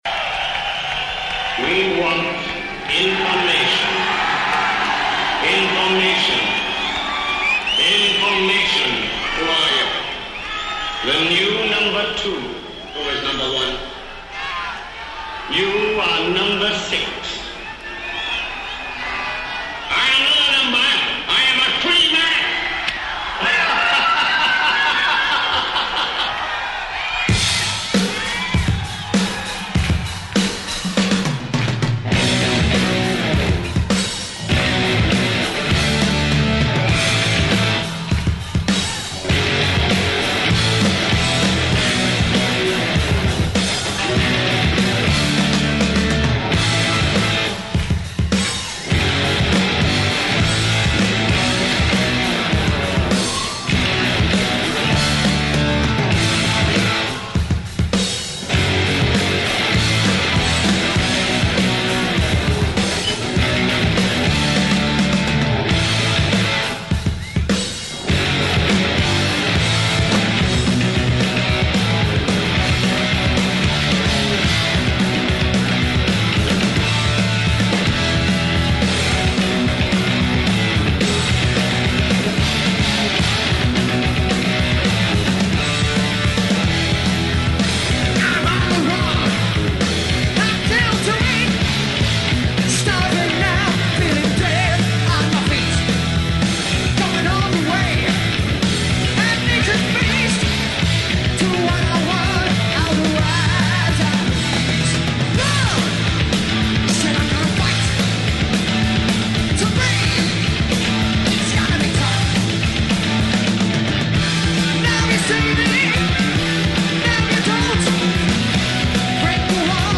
Live Concert NWOBHM